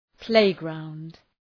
Προφορά
{‘pleıgraʋnd}